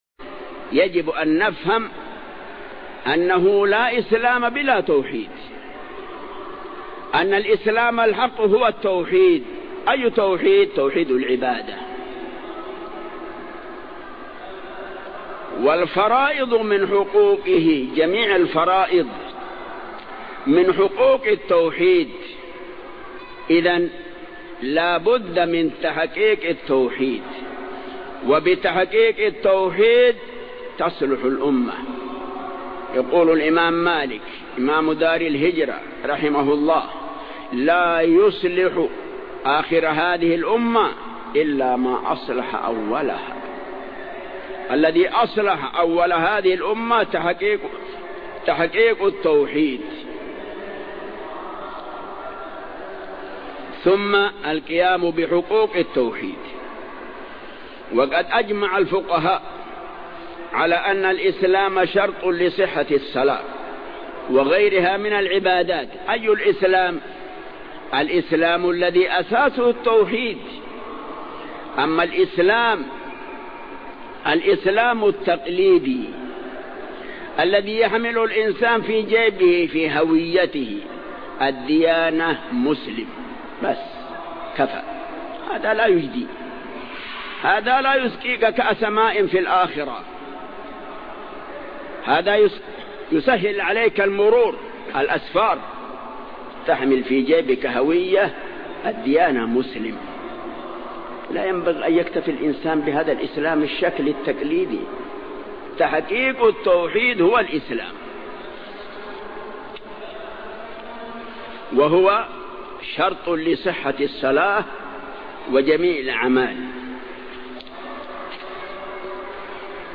Format: MP3 Mono 11kHz 32Kbps (CBR)